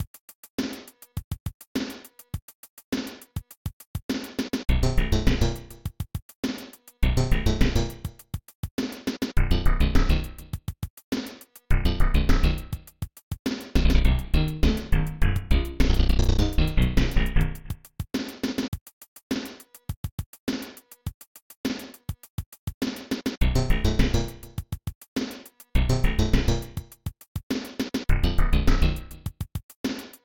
Underground theme
Copyrighted music sample